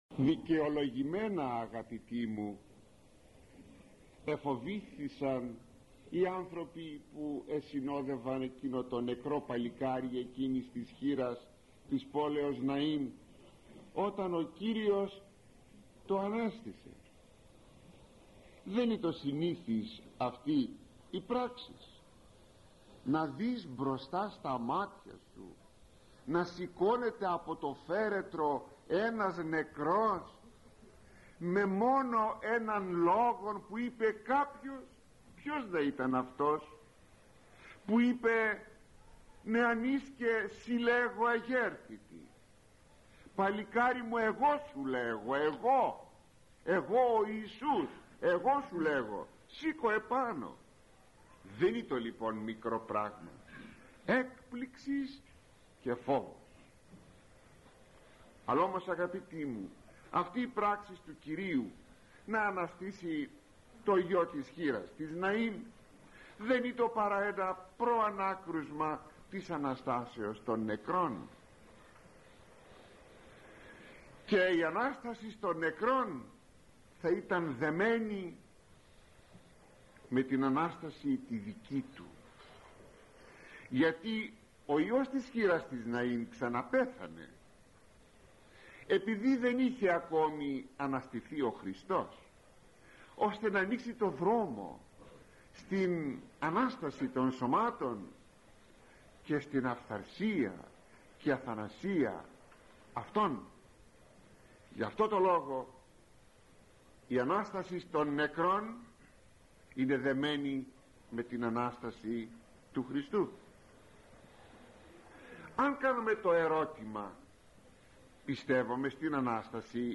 Κυριακή Γ’ Λουκά: Ανάστασις νεκρών – ηχογραφημένη ομιλία